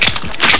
WeapPick.ogg